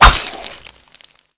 jumphit.wav